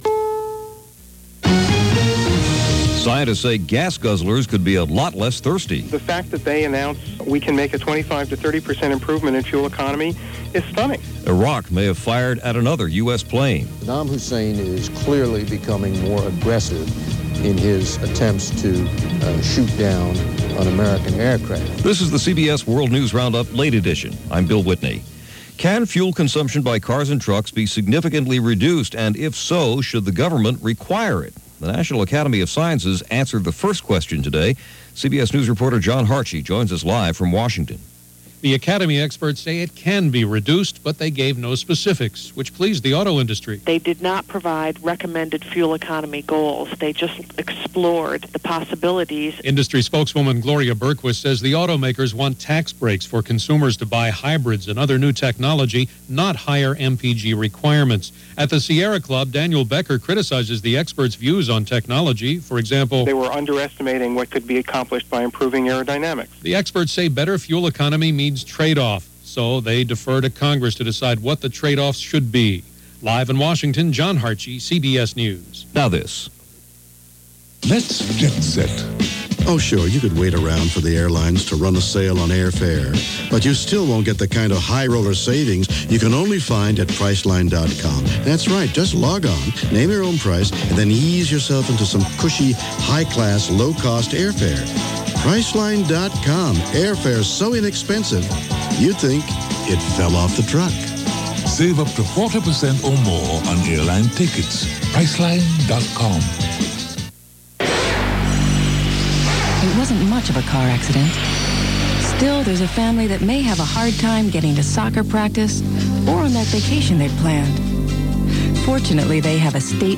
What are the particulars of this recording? And that’s a little of what went on, this July 30th 2001 as reported by The CBS World News Roundup: Late Edition.